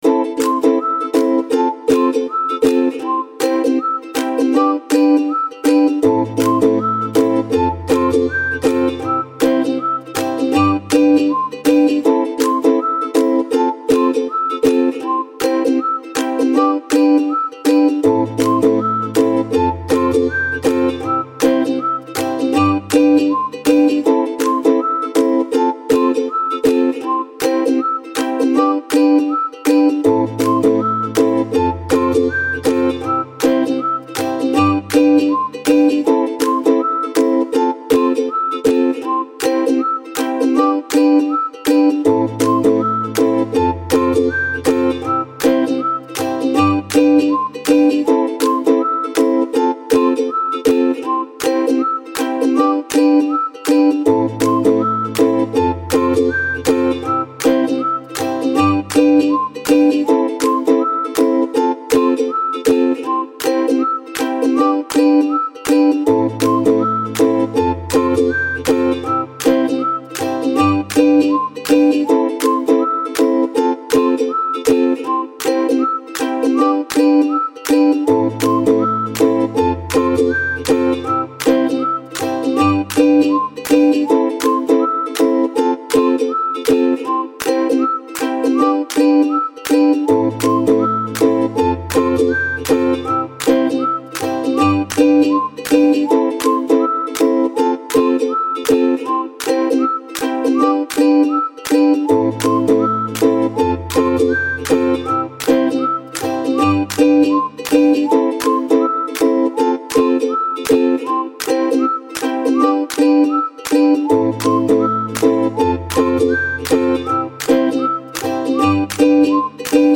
Простая мелодия для укулеле